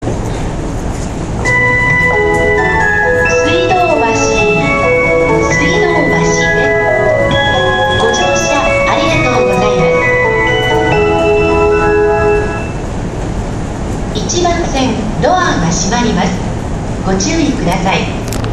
ご当地発メロ集